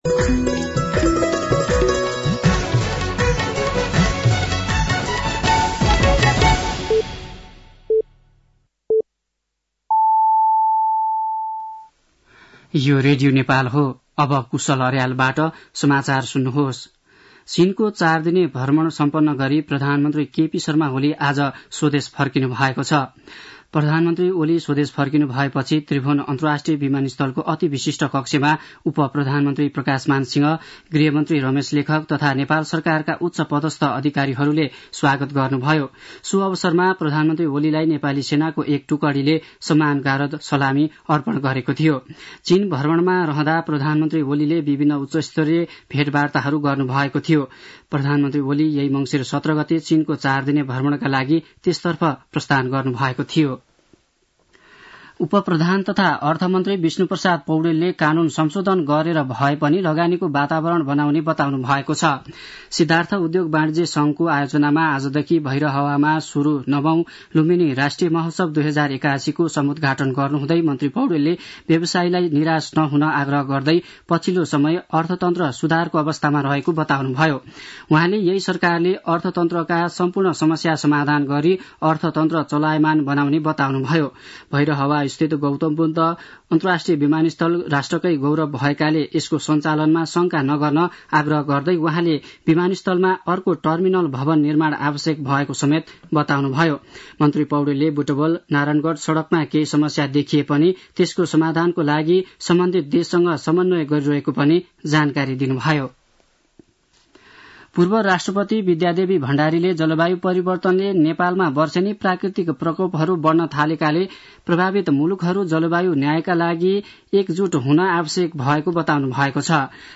साँझ ५ बजेको नेपाली समाचार : २१ मंसिर , २०८१